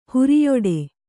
♪ huriyoḍe